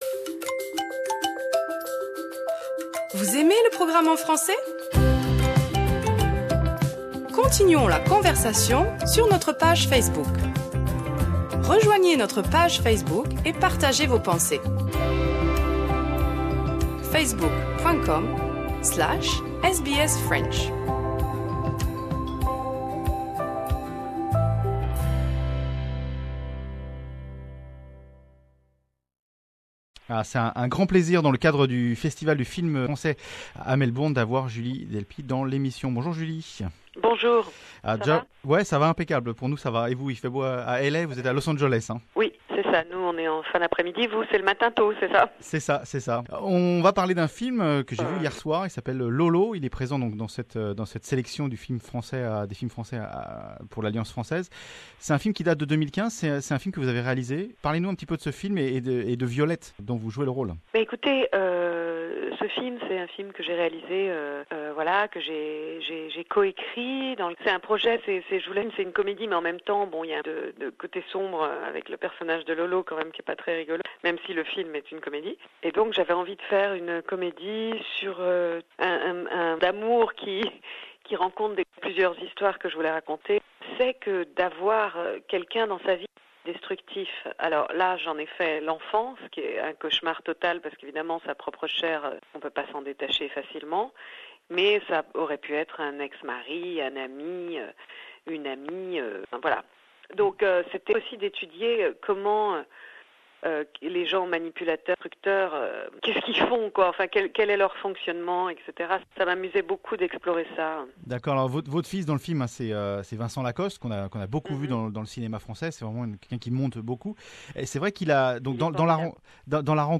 Rencontre avec Julie Delpy, actrice, réalisatrice, elle nous parle de son film, present au Festival du Film Francais de l'Alliance Francaise ... Lolo.